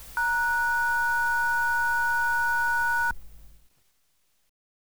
answeringmachinebeep.wav